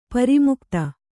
♪ pari mukta